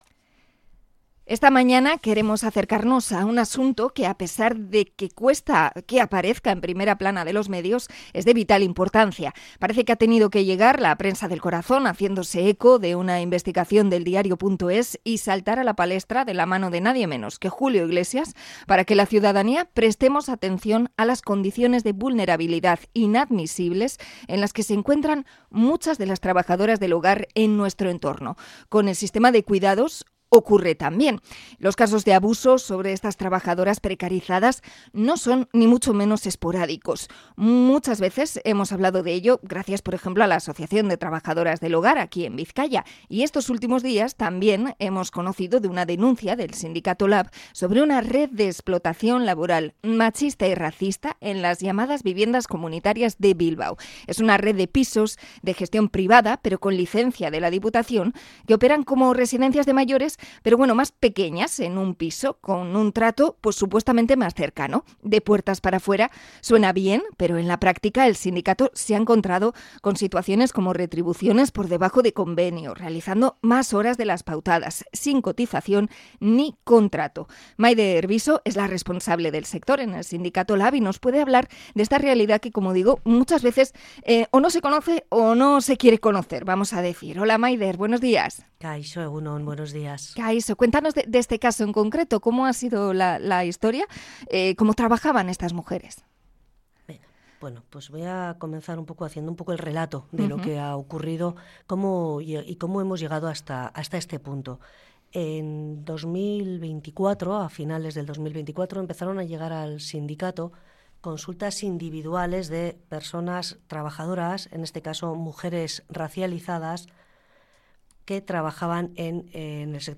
Entrevista a LAB por la precariedad